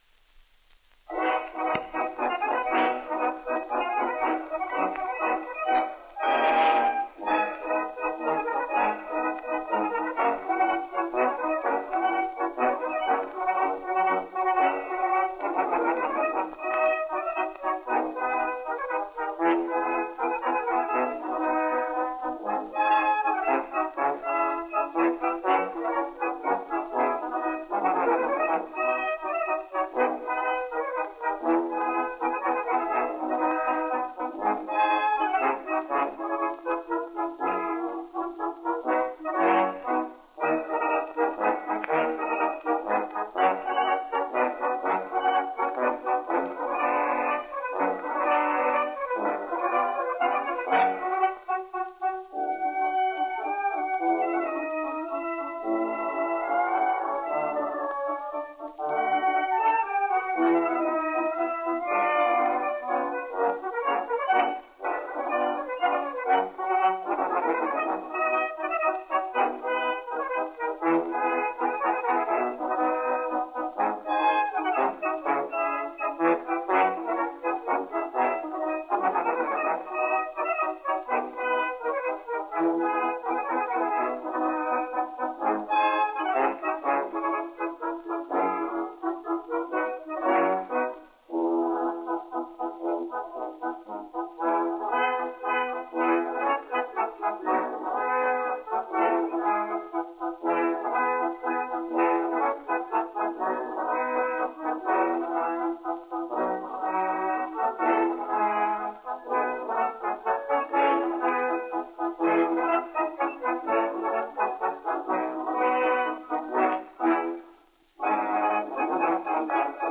Jest to wiązanka narodowych melodii, gdzie „Pieśń Legionów” można dosłownie „rozpoznać” po ok.1.5 min odsłuchiwania tego nagrania.
Okazuje się, iż nie tylko walca można grać na cztery: naszego mazurka w rytmie poloneza również…